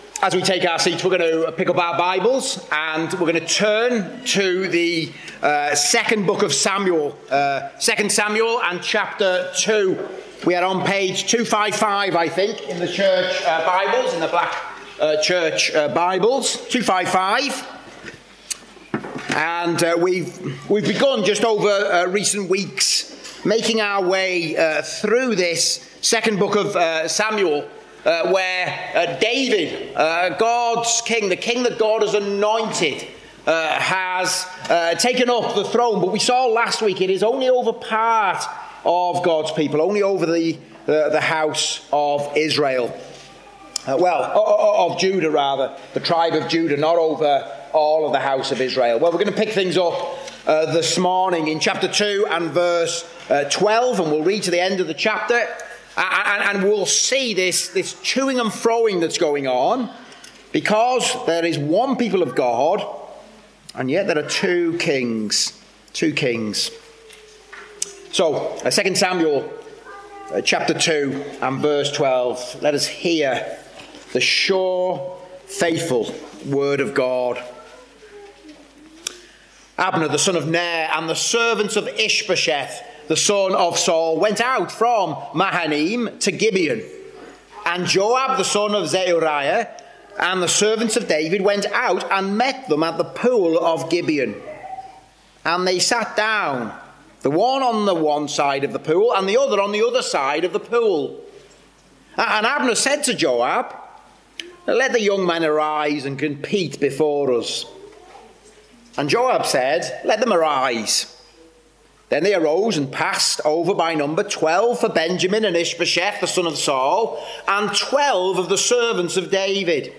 Service Type: Preaching